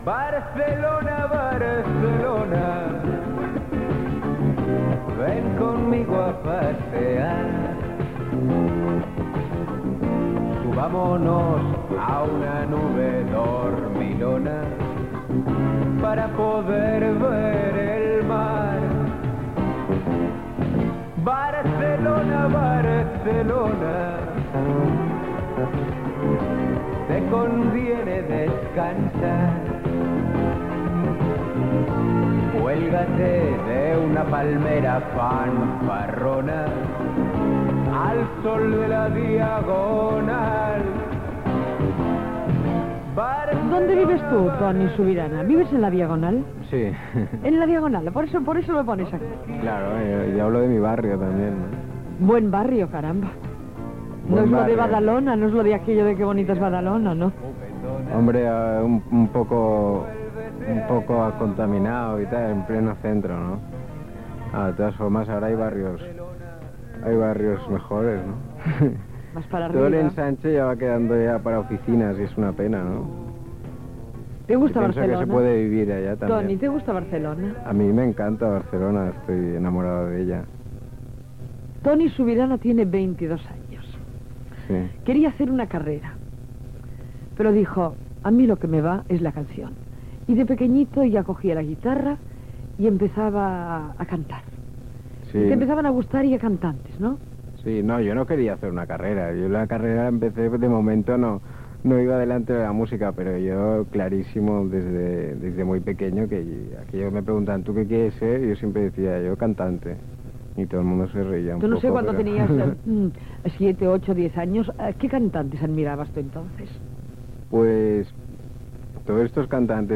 Trucada telefònica d'una oïdora.